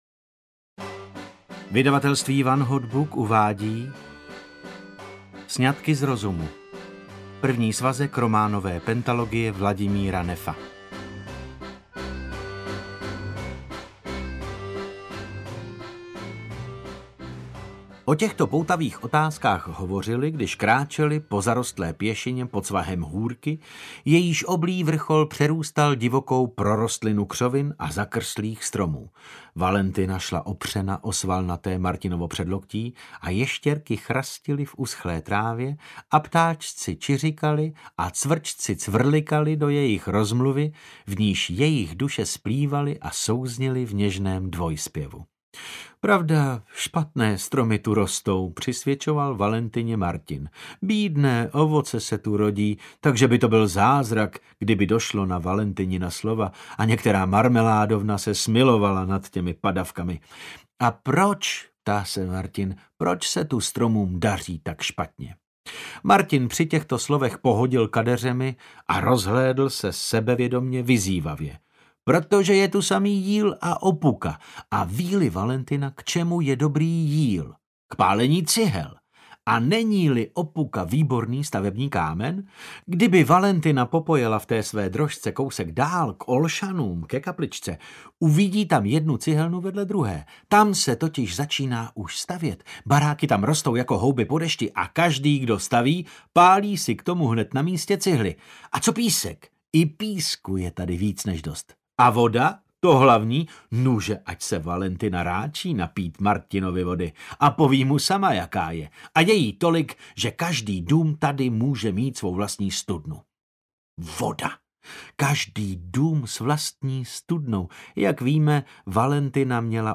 Sňatky z rozumu audiokniha
Ukázka z knihy